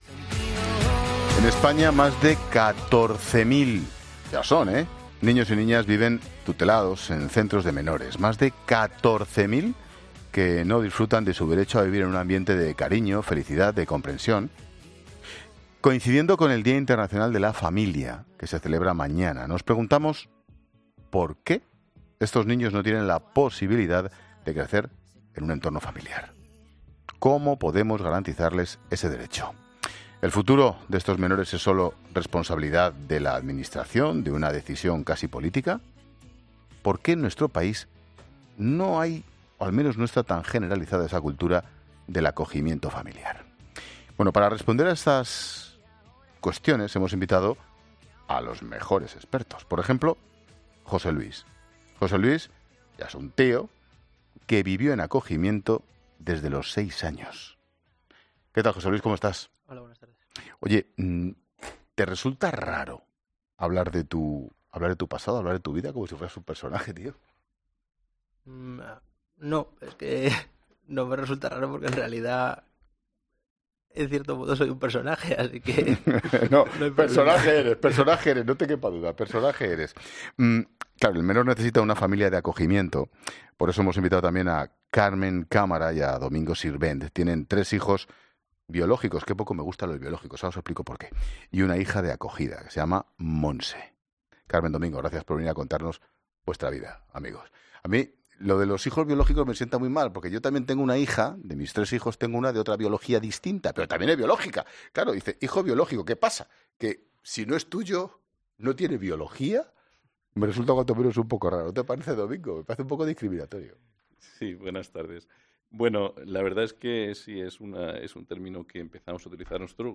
ESCUCHA LA ENTREVISTA COMPLETA EN 'LA TARDE' Lamenta que en España haya más de 14.000 niños y niñas que no disfrutan de su derecho a crecer en familia e insiste en que el acogimiento familiar es la solución por derecho para estos menores.